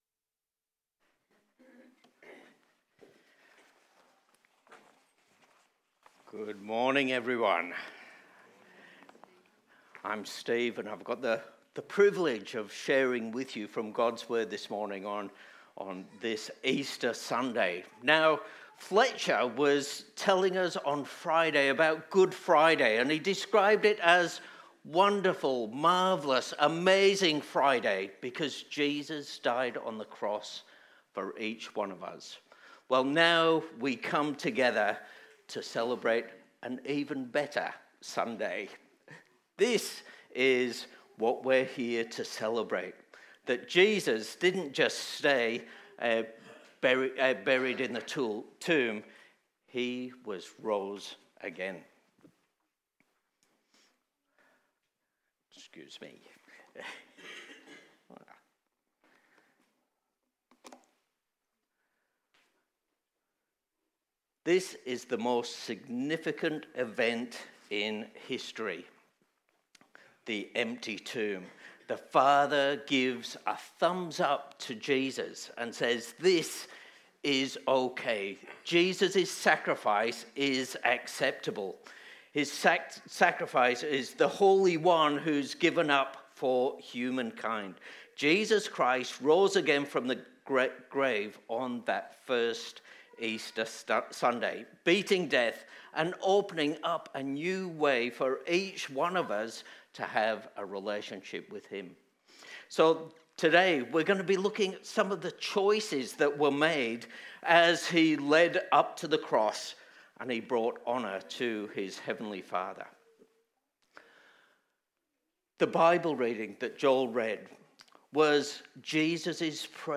KPCC Sermons | Kings Park Community Church